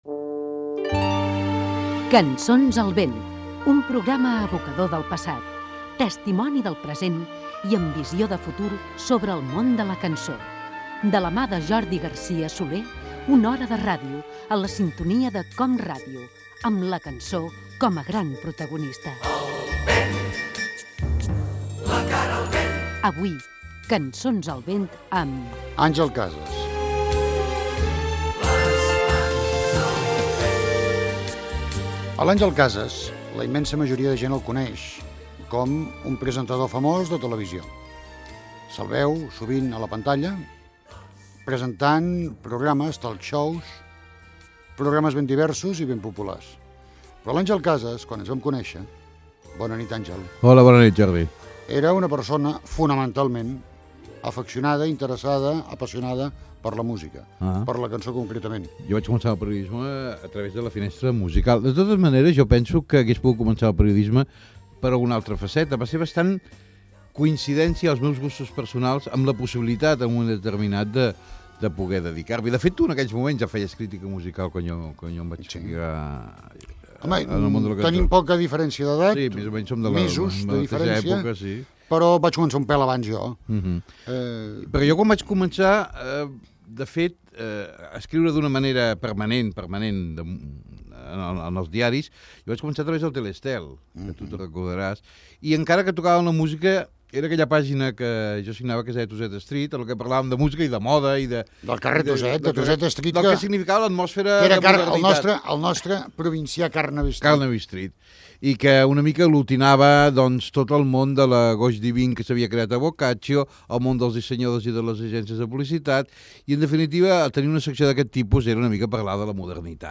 Careta del programa, presentació i fragment d'una entrevista amb la música que escoltava el presentador Àngel Casas.
Divulgació
FM